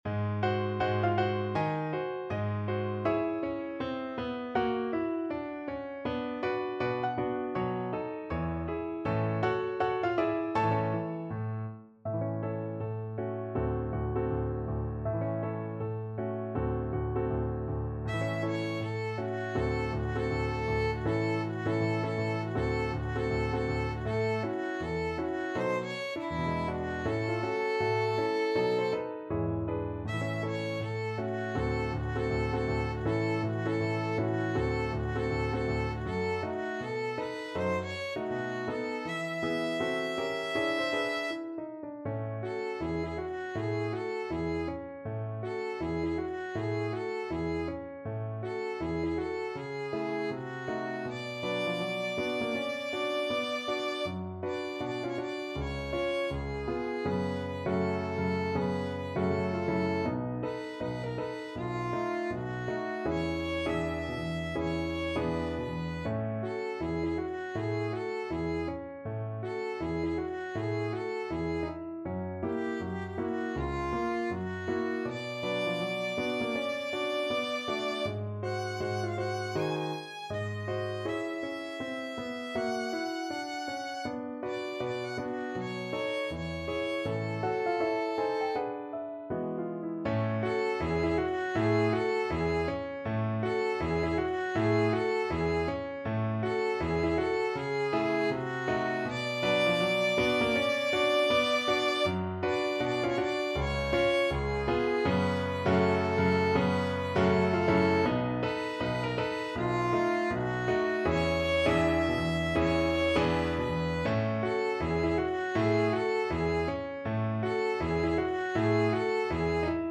2/2 (View more 2/2 Music)
~ = 160 Moderato
E5-Ab6
Jazz (View more Jazz Violin Music)
Rock and pop (View more Rock and pop Violin Music)